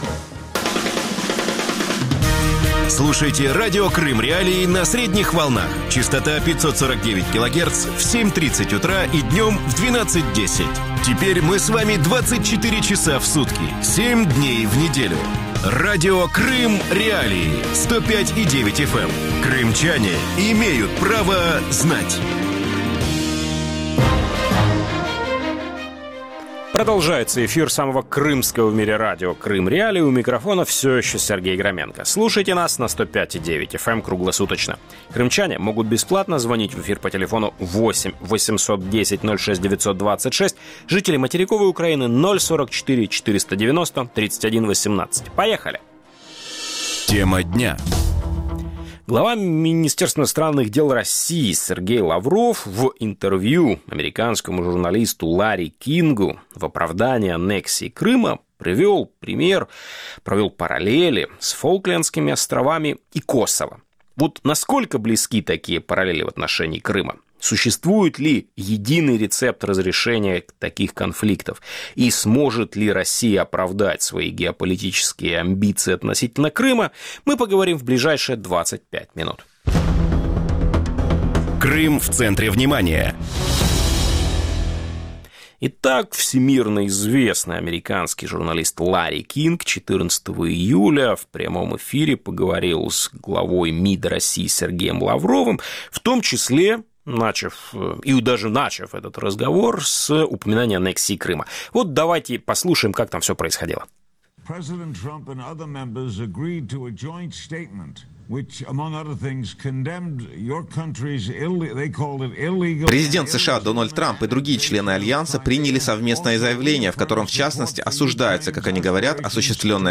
Андрей Зубов, российский историк, религиовед и политолог
Радио Крым.Реалии в эфире 24 часа в сутки, 7 дней в неделю.